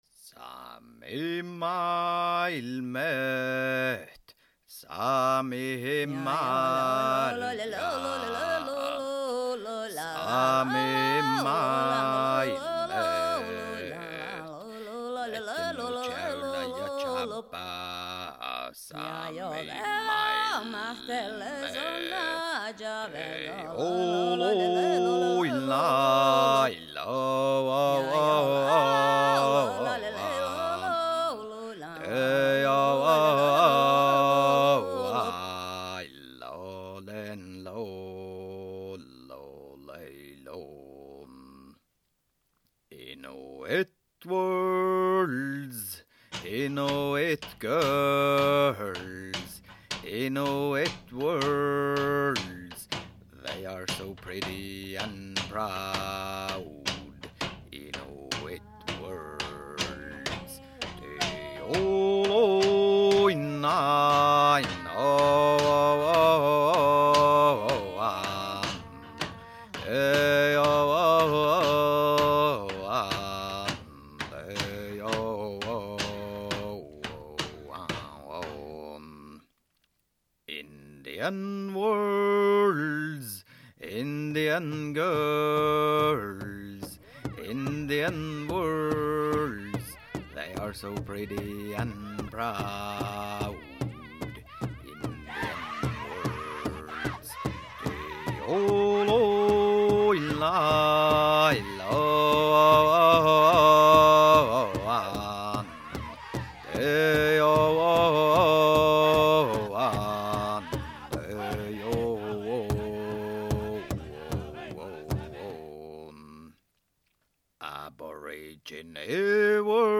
Finally Some yoiks